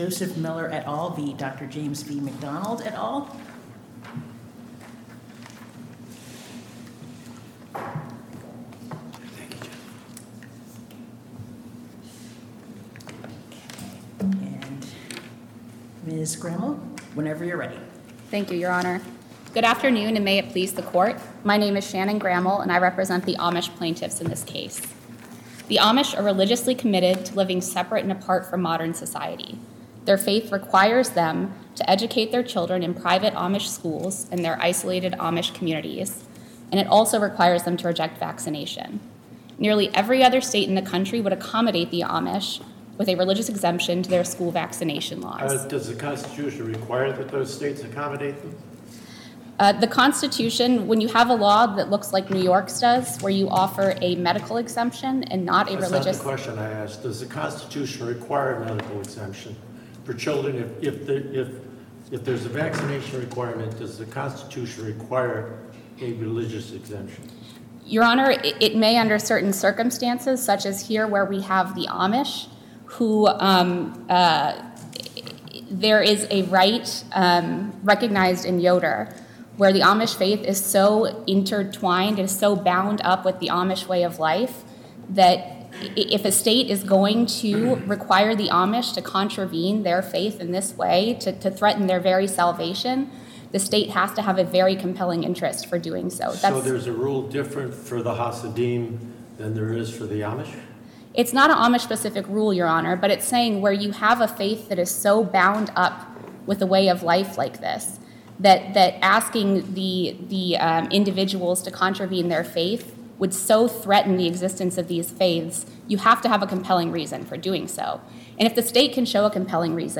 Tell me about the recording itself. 2nd Circuit Hears Oral Arguments from Amish Seeking Vaccination Exemptions